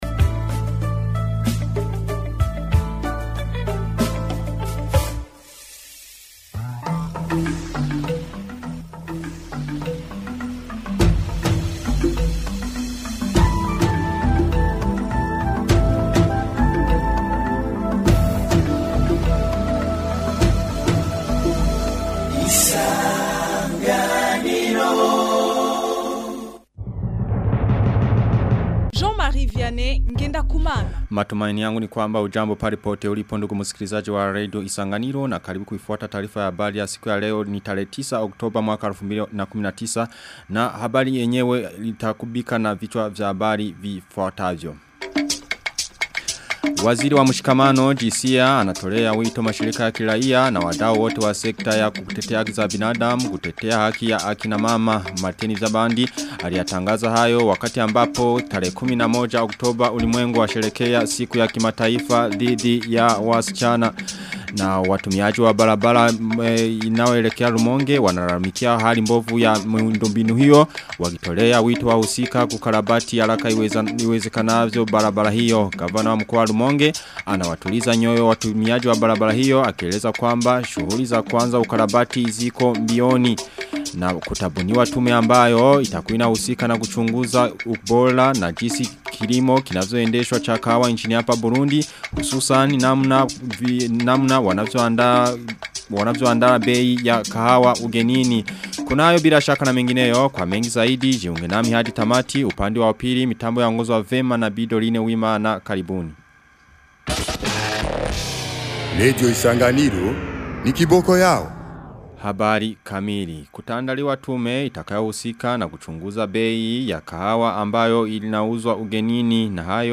Taarifa ya habari 09 oktoba 2019